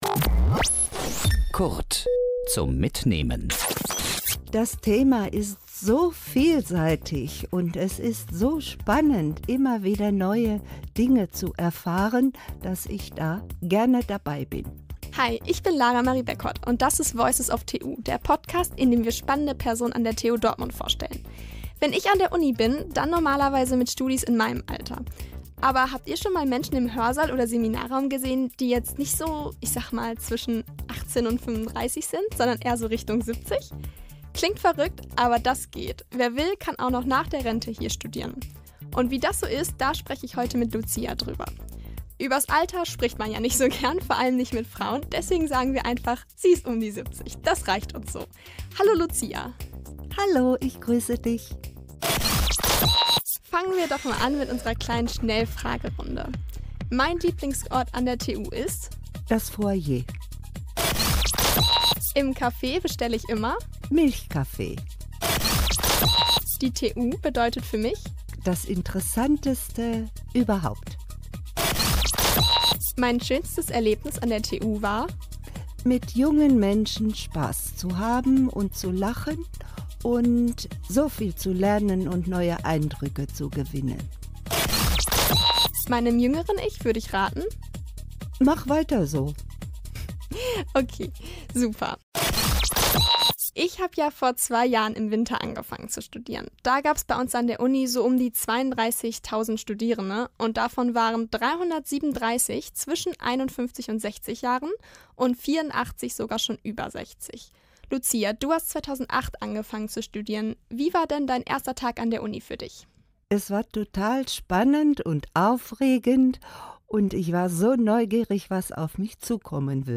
NRWision Podcast